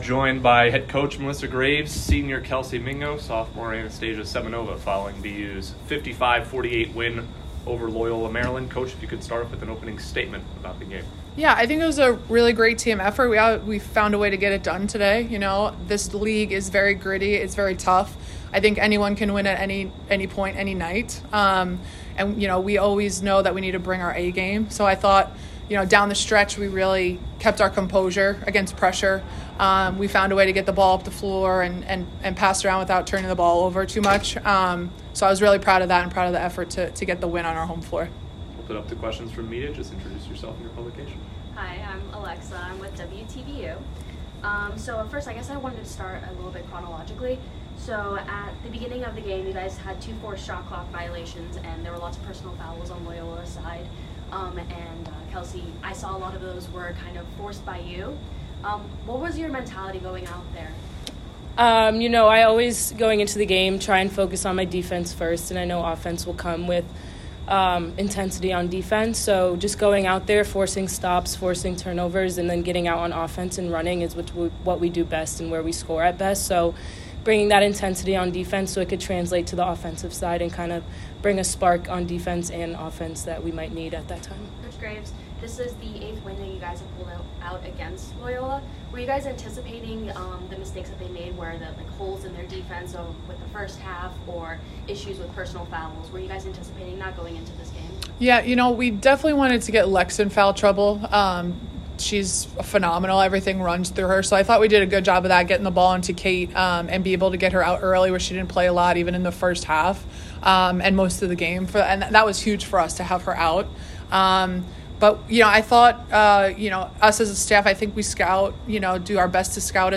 Women's Basketball - Loyola Maryland Postgame Press Conference (1-13-24)